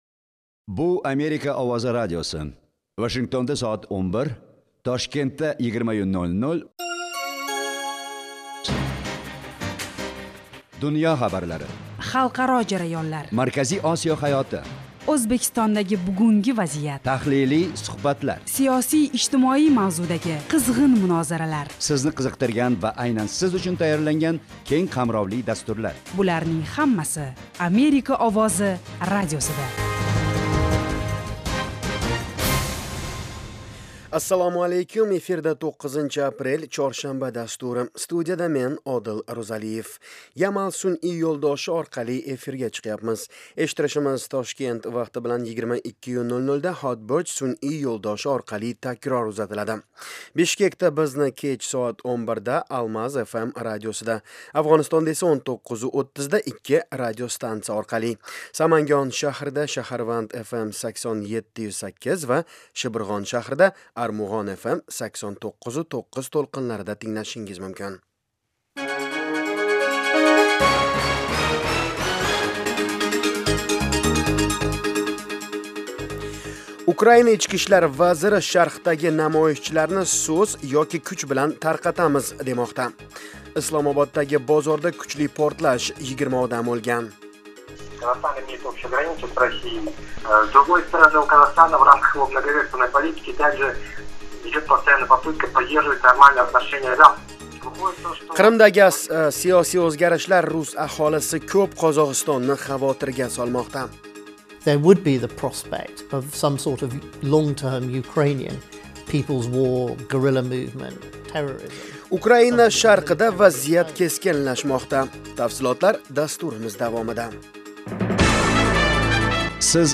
Toshkent vaqti bilan har kuni 20:00 da efirga uzatiladigan 30 daqiqali radio dastur kunning dolzarb mavzularini yoritadi. O'zbekiston va butun Markaziy Osiyodagi o'zgarishlarni tahlil qiladi. Amerika bilan aloqalar hamda bu davlat siyosati va hayot haqida hikoya qiladi.